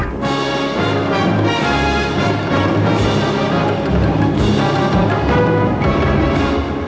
End of Theme